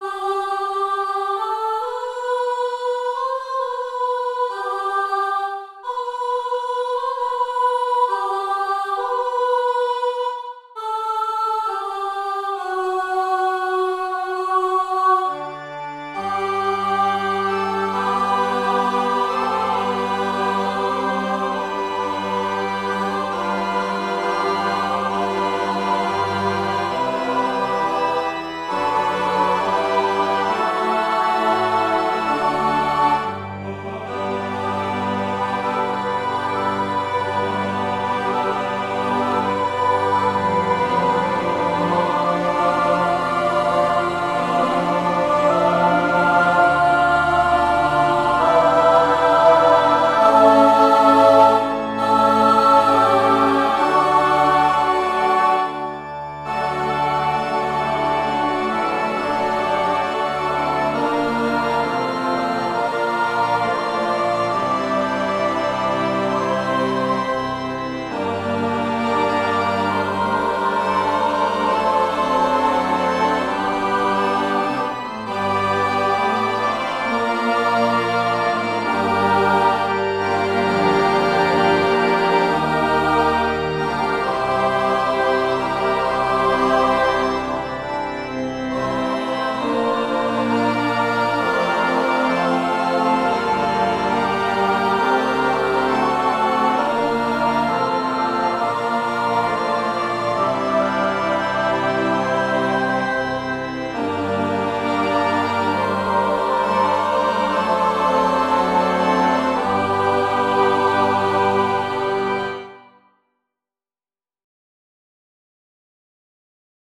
Voicing/Instrumentation: SSATB , Organ/Organ Accompaniment